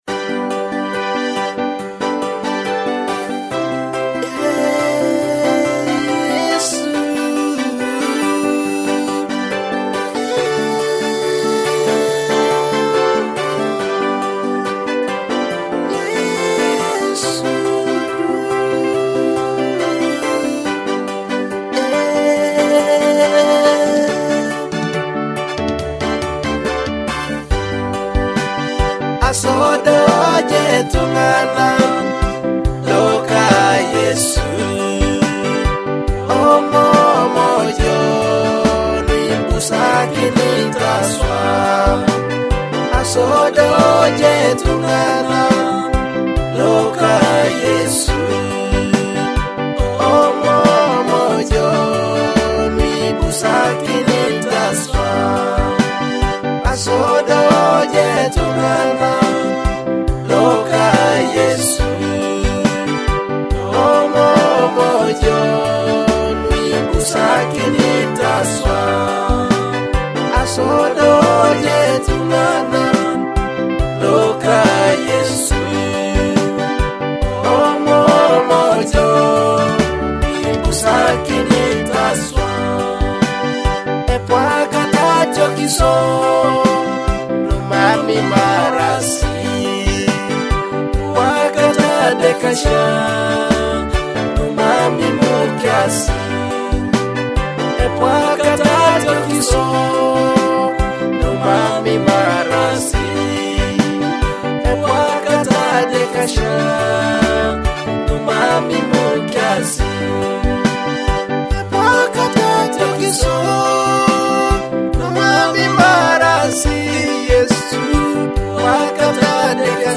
uplifting gospel music